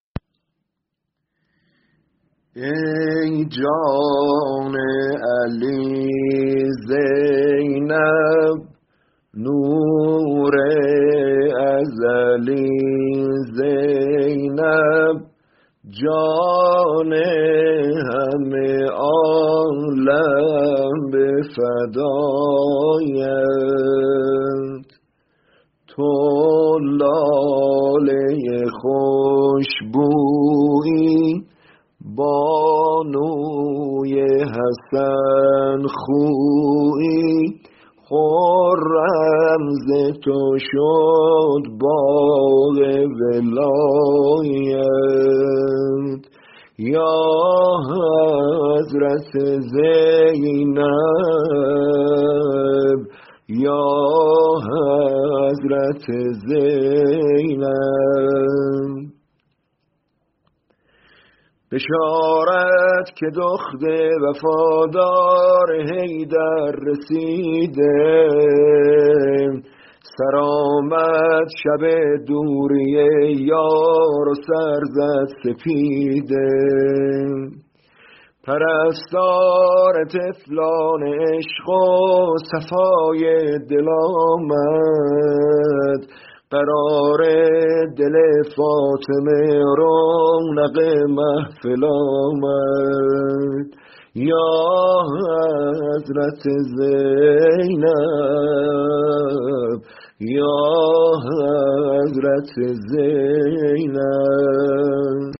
مدح و میلادیه